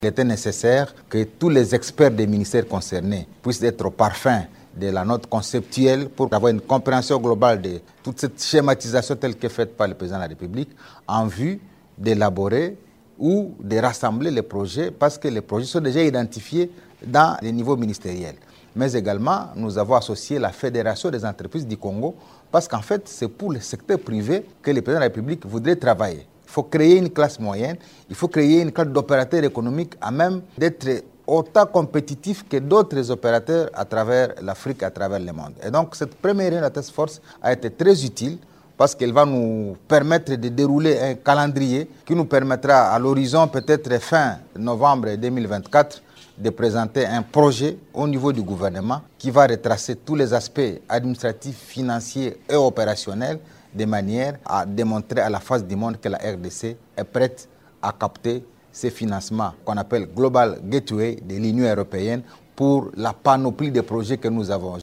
Vous pouvez écouter Julien Paluku dans cet extrait :